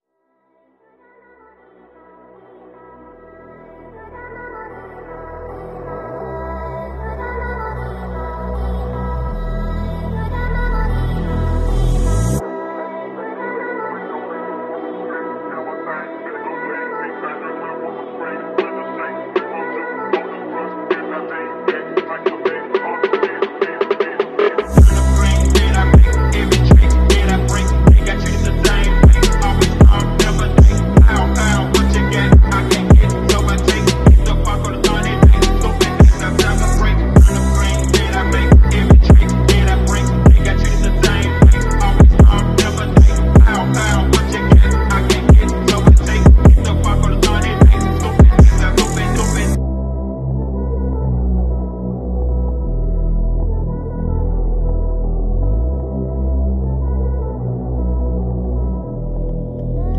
Audi a4 B6 1.8T antilag/launch sound effects free download
Audi a4 B6 1.8T antilag/launch control